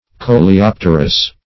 Search Result for " coleopterous" : The Collaborative International Dictionary of English v.0.48: Coleopteral \Co`le*op"ter*al\, Coleopterous \Co`le*op"ter*ous\a. [Gr.